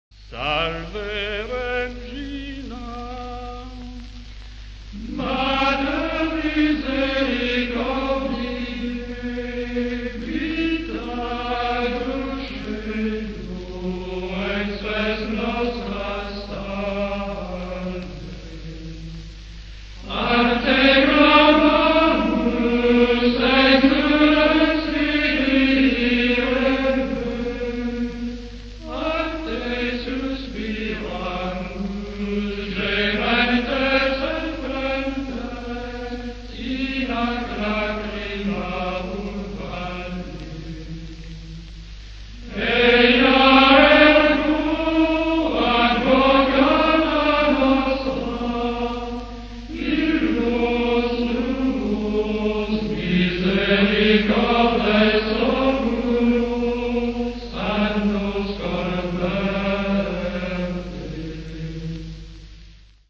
Стиль пения, принятый в Солеме, известен необычайной ритмической гибкостью, порождающей особый созерцательный эффект.
Choir of the Abbey of Saint-Pierre de Solesmes.
Хор братии солемского аббатства св. Петра, орден св. Бенедикта.